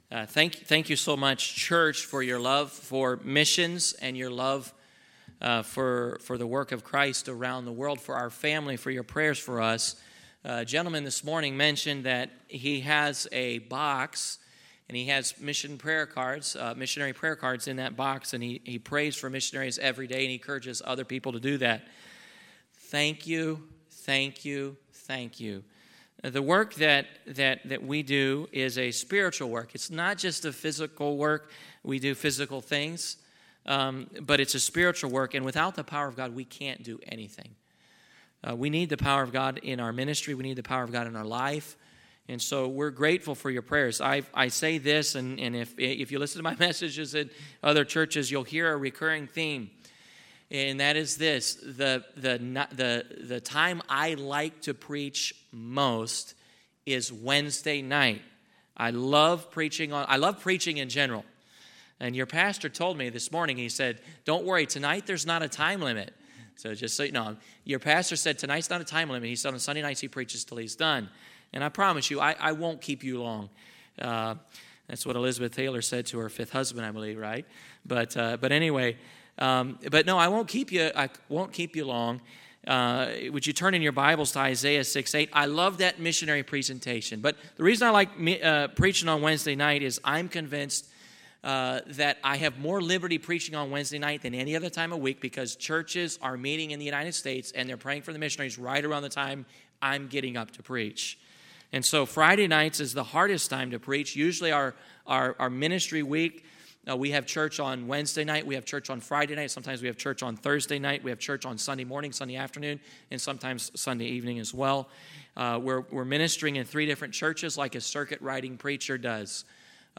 2025 Missions Conference , Sermons